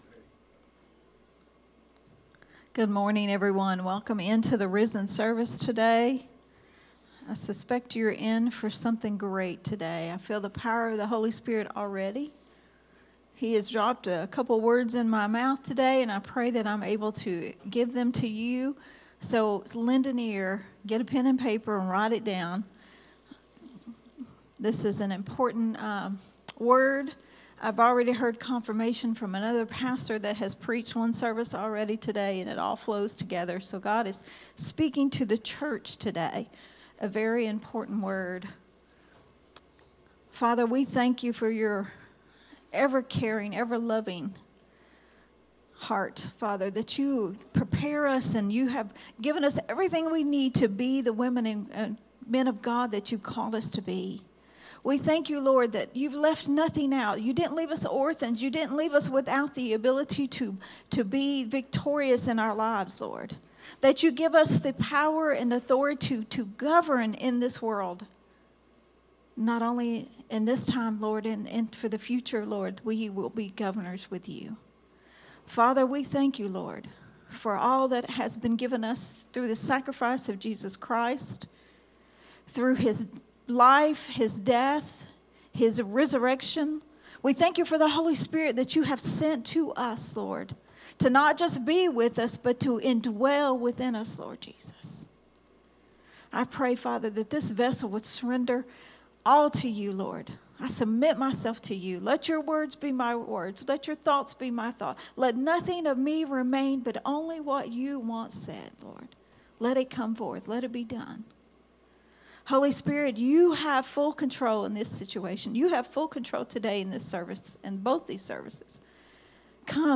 a Sunday Morning Risen Life teaching
recorded at Growth Temple Ministries on Sunday March 2, 2025.